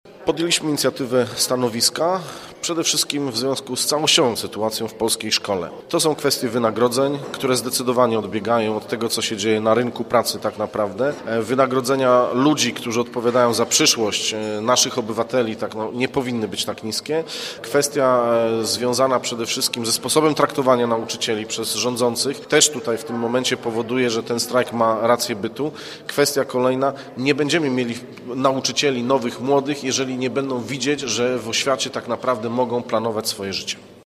Treść stanowiska w tej sprawie przygotowali radni z klubu Koalicji Obywatelskiej. Mówi przewodniczący klubu Radosław Wróblewski: